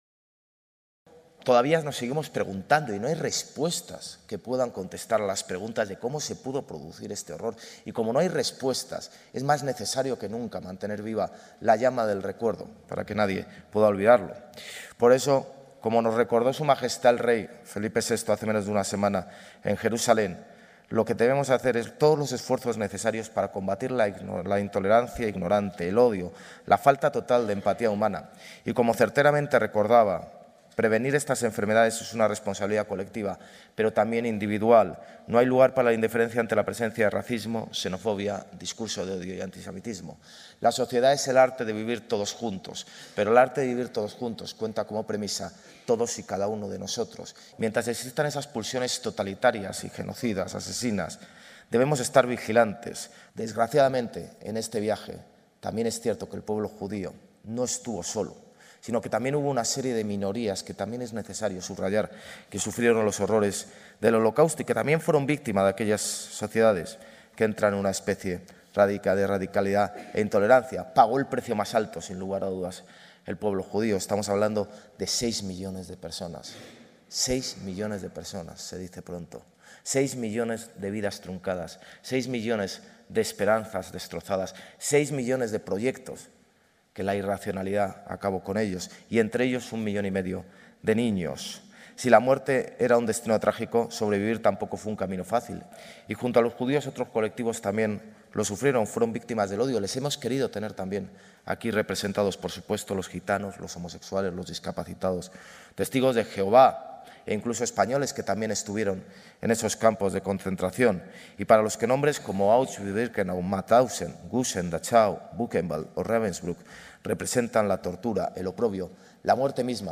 Nueva ventana:Almeida en el acto con motivo del Día Oficial de la Memoria del Holocausto